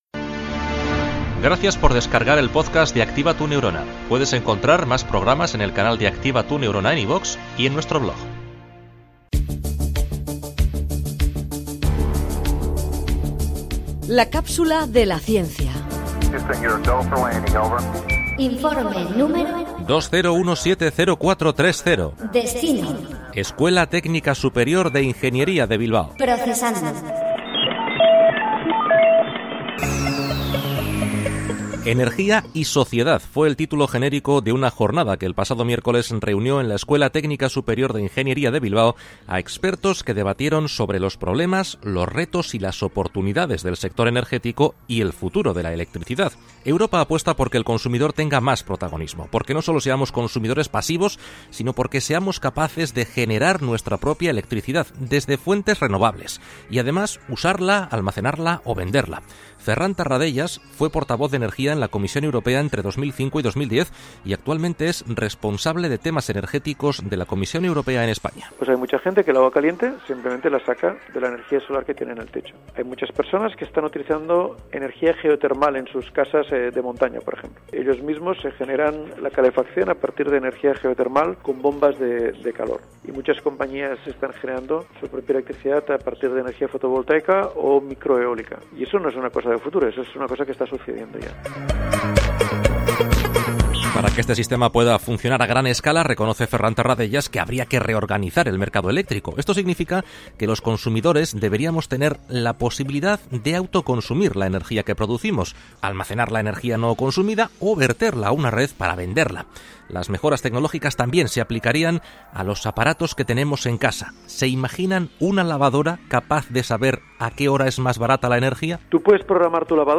La comunicación de la ciencia es la protagonista de 3 minutos de radio en los que colaboramos con científicos punteros para contar de forma amena y sencilla los resultados de sus últimas investigaciones.